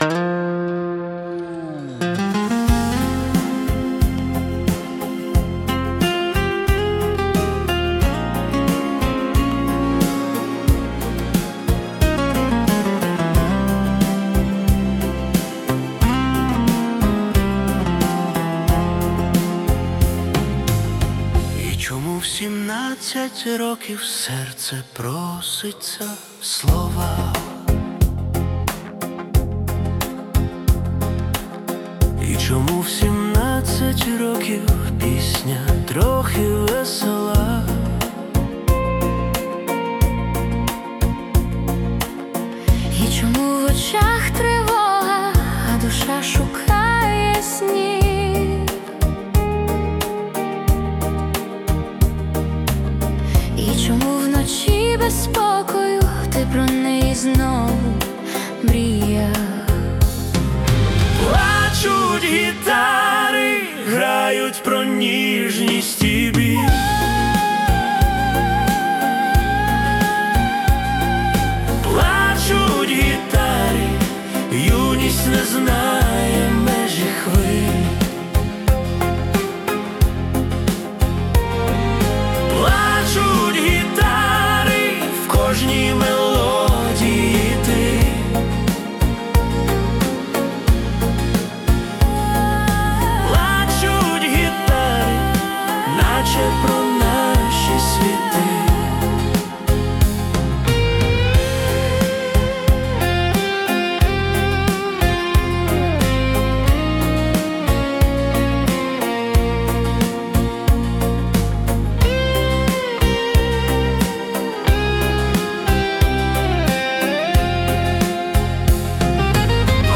Soviet Estrada Ballad (70s Style)
теплий аналоговий звук, душевний дует і романтика юності.